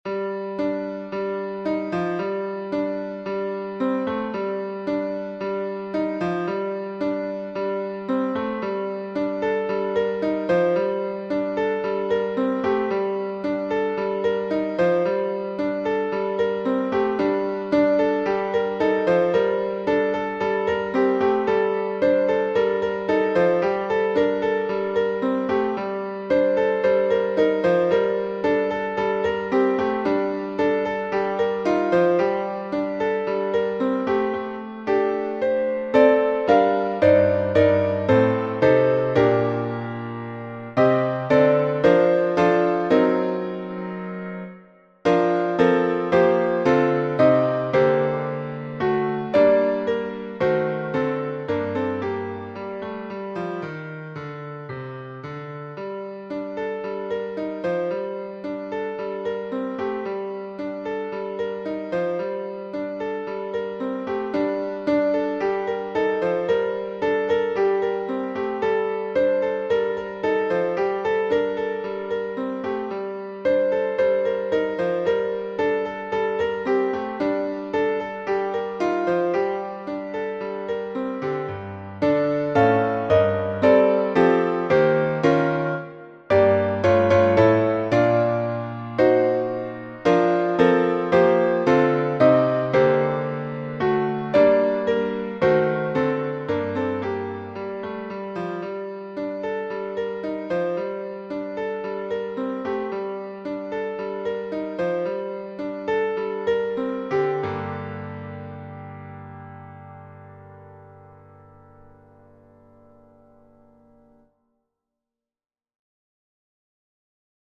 Version Piano